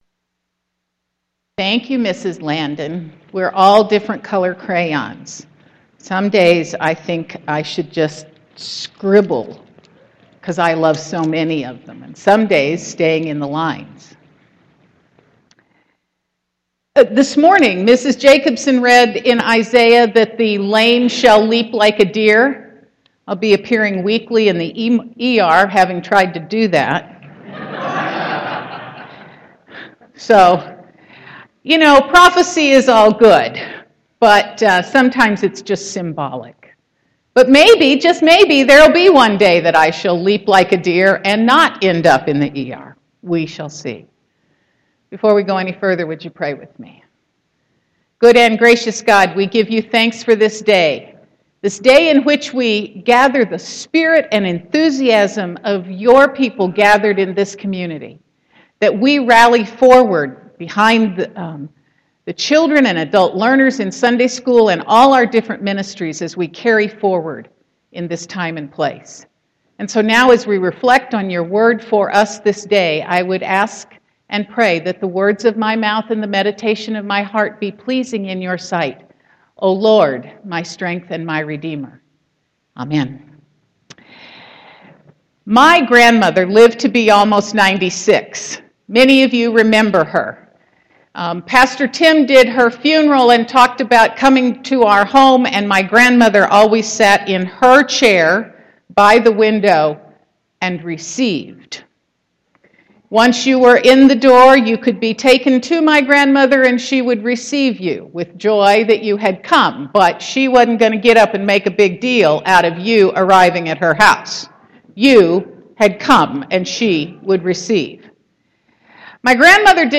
Sermon 9.6.2015